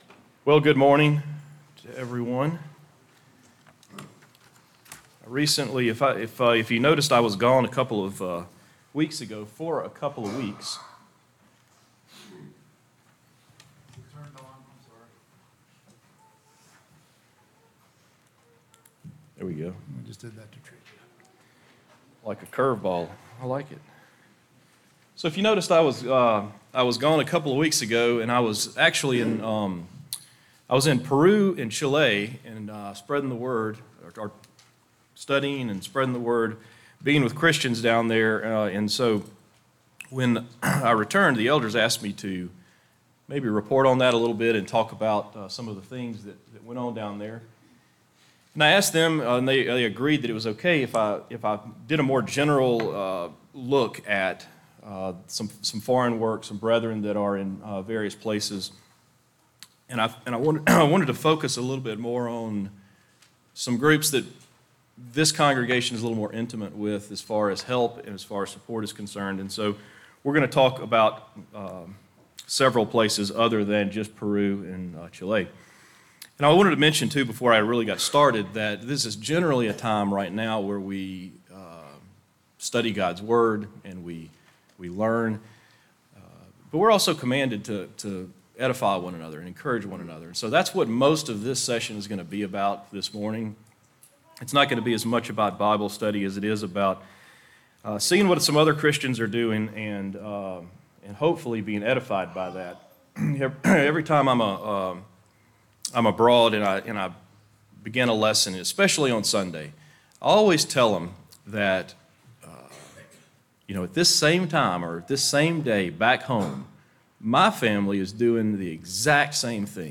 Sermons - Benchley church of Christ